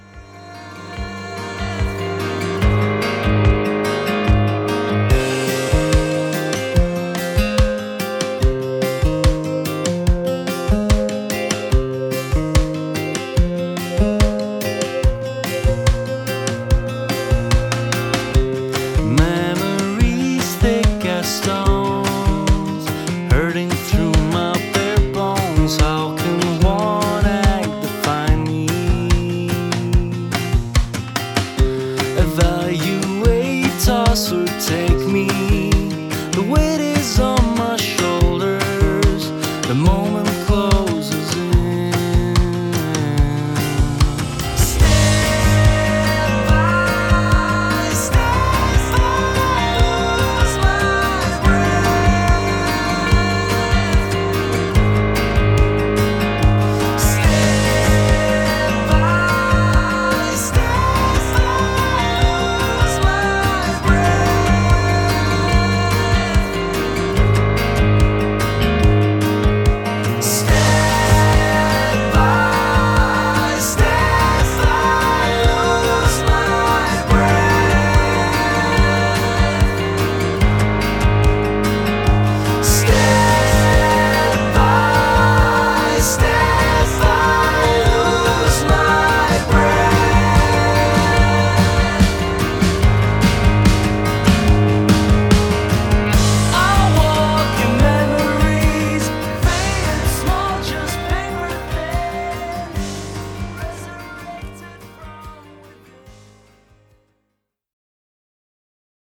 PORTFOLIO – DRUM RECORDINGS
PROGRESSIVE POP